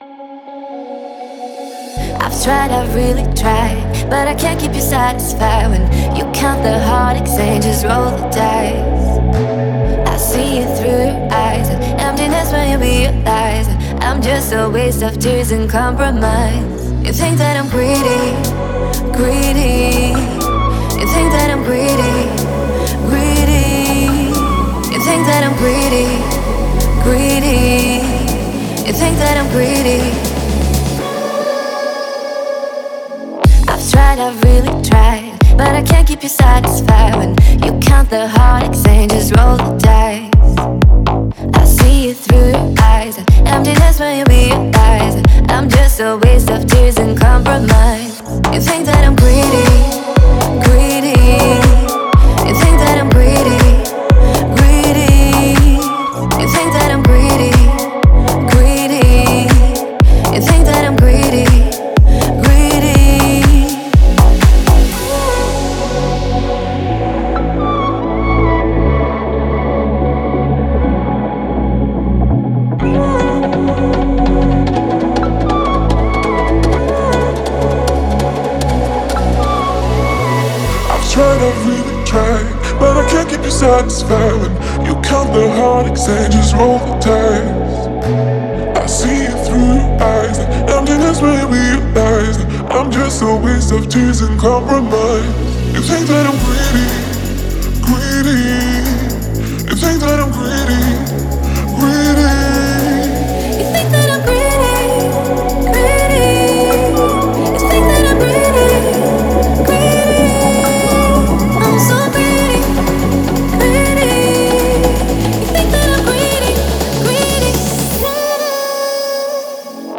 это энергичная трек в жанре хип-хоп с элементами R&B